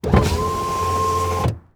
windowpart1.wav